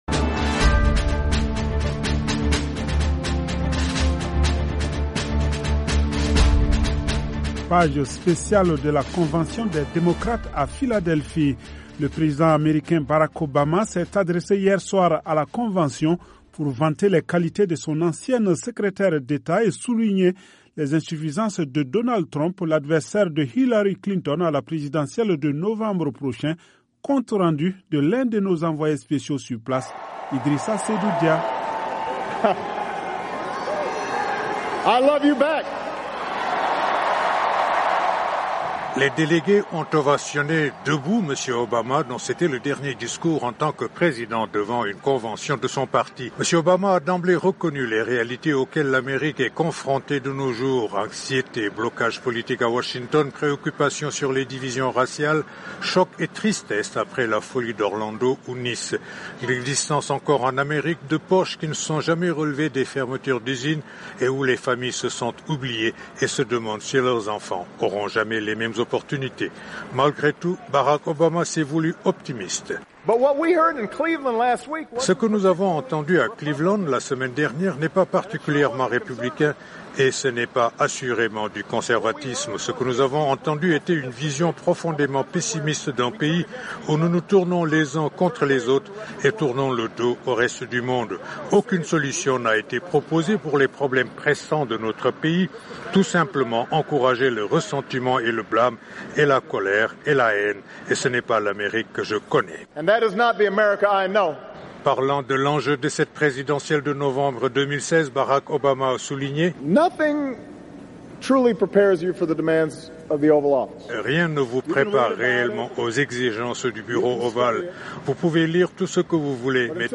Convention des démocrates.
Le compte rendu de notre envoyé spcécial